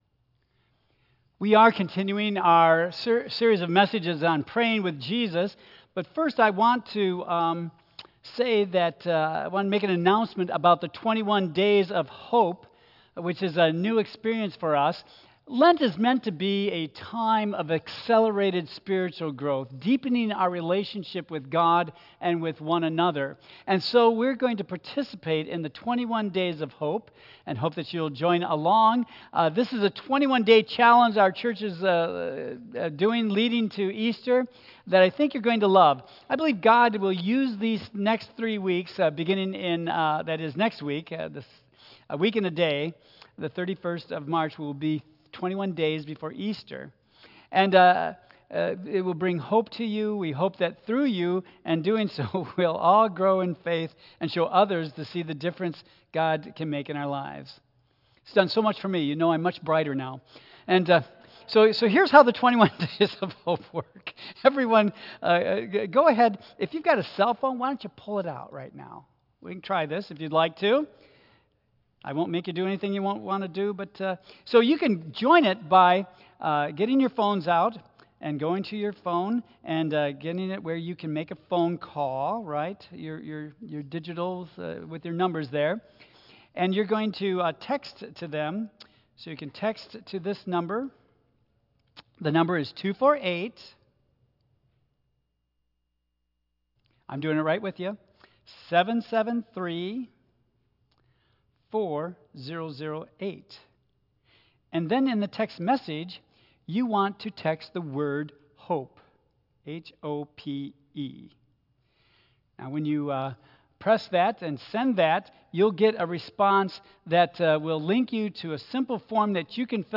Tagged with 2025 , Lent , Sermon , Waterford Central United Methodist Church , Worship Audio (MP3) 11 MB Previous Prayer in Times of Trouble Next The High Priestly Prayer (Part 1)